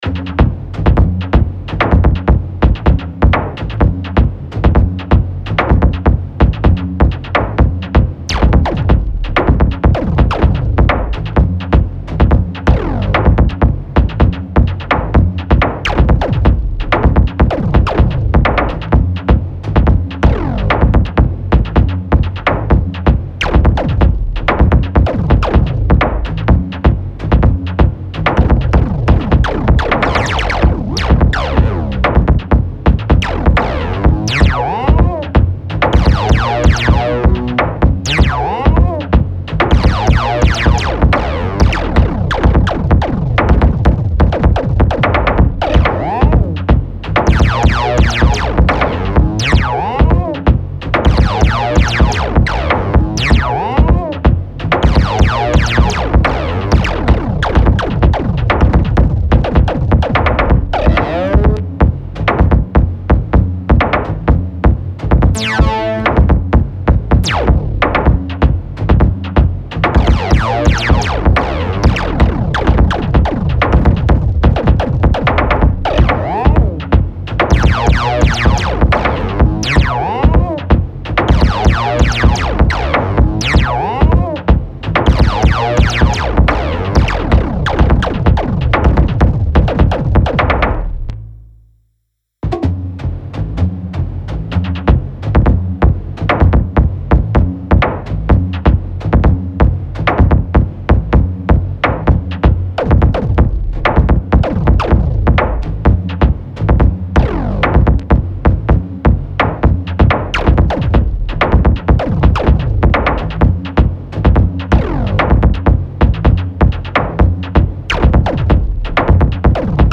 Покрутил Thump-one очень классный синт несмотря на свой не красивый интрейейс очень интересные идеи. есть правда некоторые траблы на верхней середине маловато. небольшое демо с обработкой мастер микса внутри все смиксовано с помощью ringmod side chain.